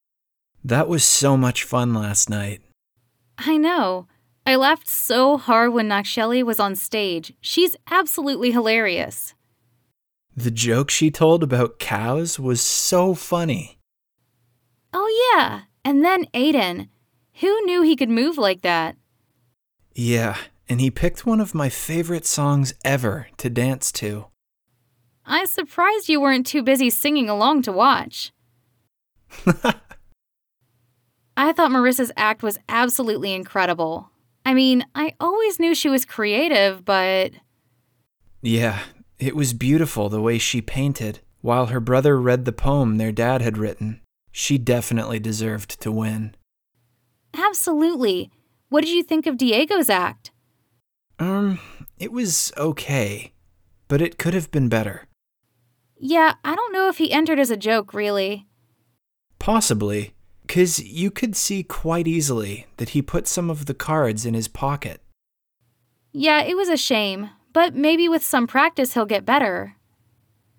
RH5 1.2_conversation.mp3